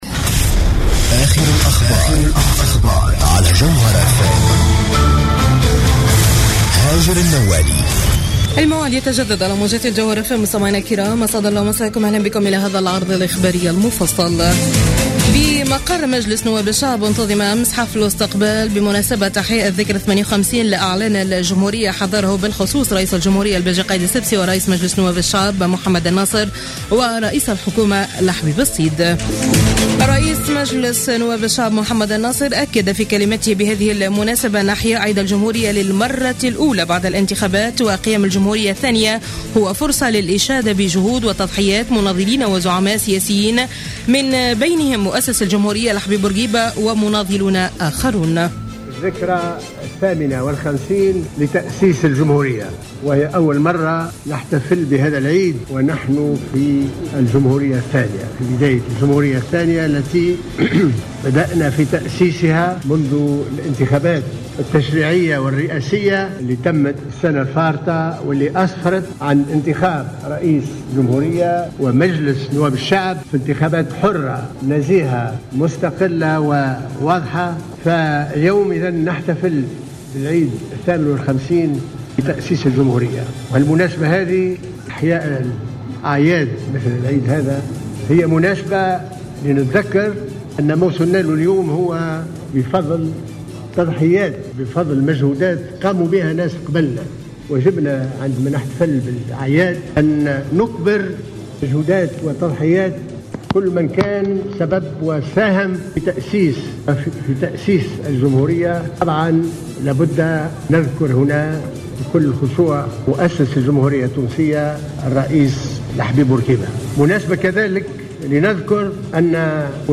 نشرة أخبار منتصف الليل ليوم الاحد 26 جويلية 2015